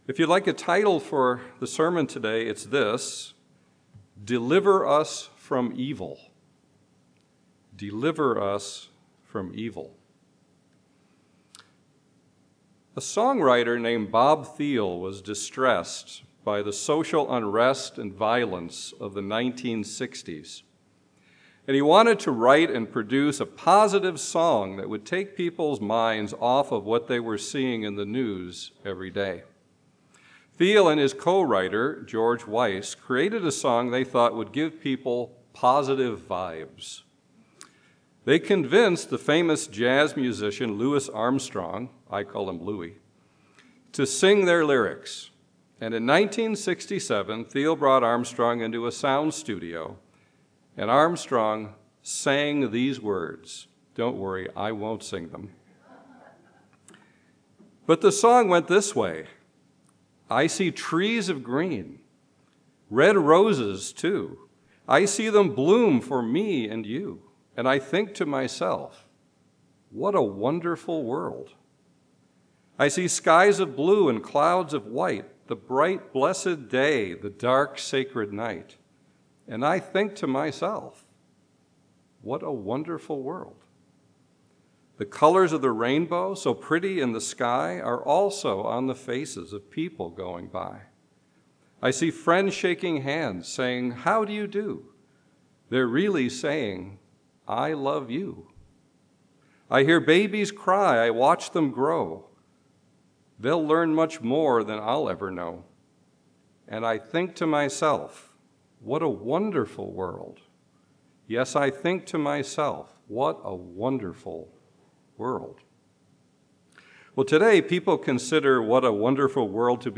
Jesus Christ told his disciples that they should pray for the Father to deliver them from the evil one. This sermon provides scriptural insight into what Jesus meant by that phrase.